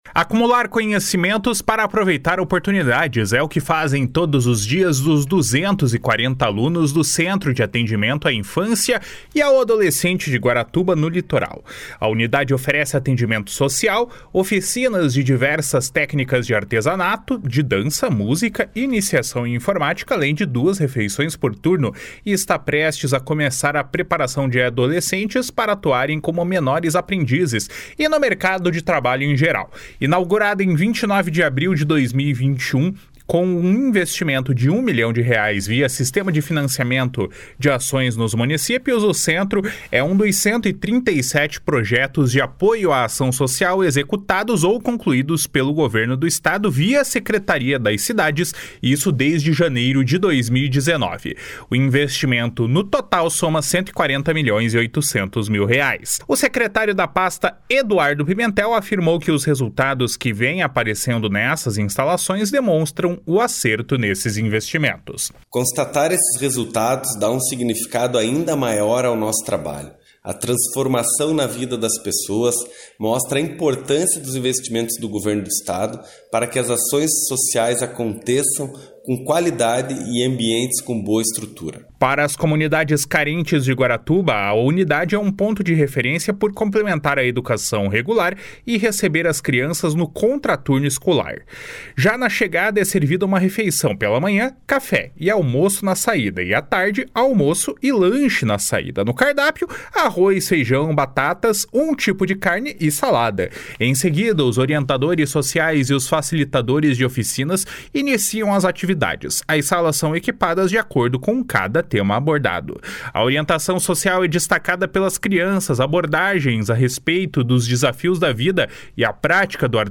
O investimento soma 140 milhões e 800 mil reais. O secretário da Pasta, Eduardo Pimentel, afirmou que os resultados que vem aparecendo nessas instalações demonstram o acerto nesses investimentos. // SONORA EDUARDO PIMENTEL //